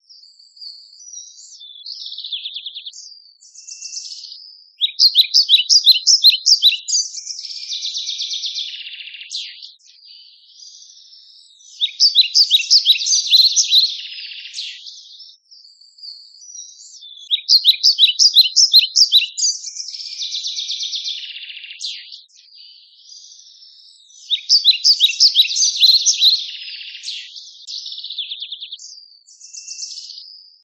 美しい小鳥たちのさえずり。